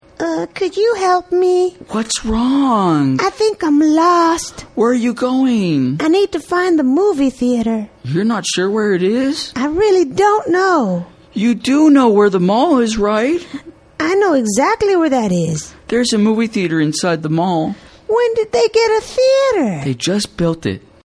情景英语对话：Where to Find a Movie Theater(2) 听力文件下载—在线英语听力室